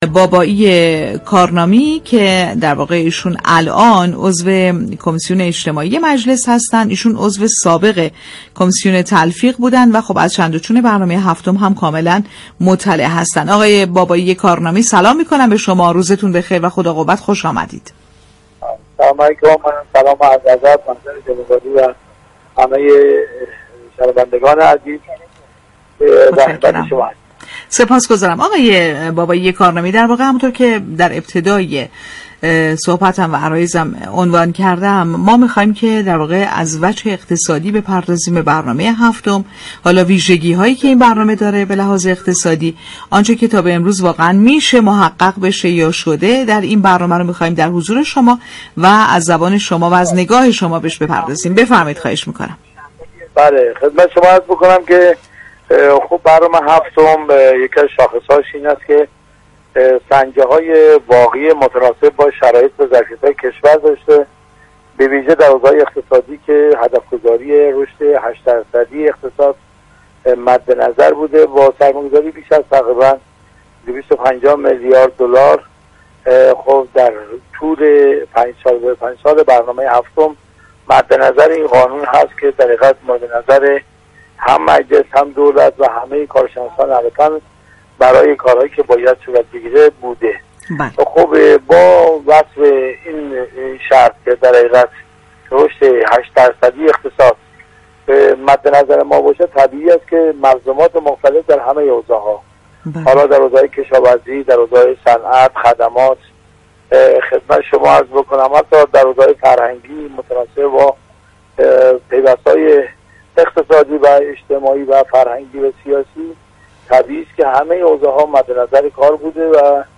به گزارش پایگاه اطلاع رسانی رادیو تهران، علی بابایی كارنامی عضو كمیسیون اجتماعی مجلس شورای اسلامی در گفت و گو «بازار تهران» اظهار داشت: برنامه پنج ساله هفتم با توجه به شرایط و ظرفیت‌های واقعی اقتصادی كشور تدوین شده است.